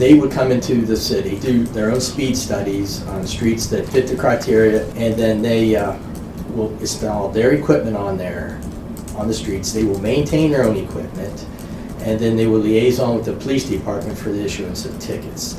During the Cumberland Mayor and City Council work session, Police Chief Chuck Ternent gave an update on the use of electronic speed enforcement signs.